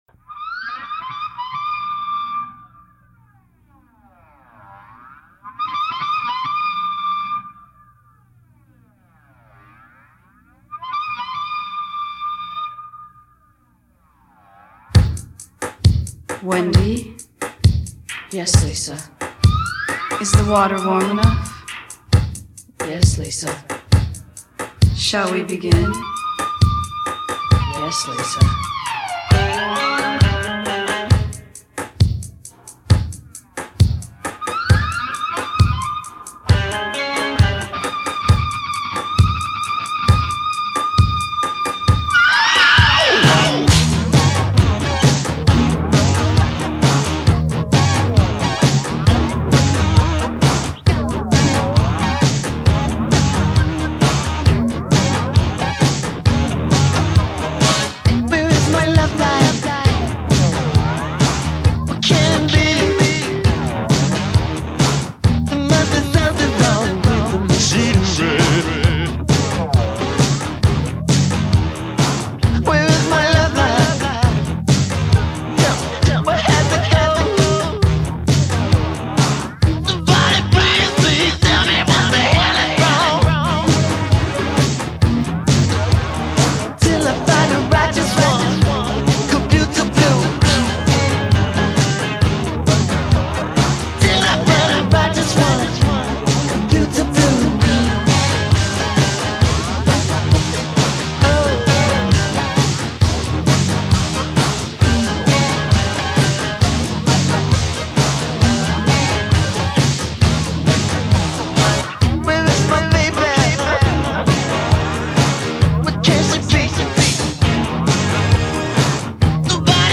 spoken-word opening
with different guitar feedback ending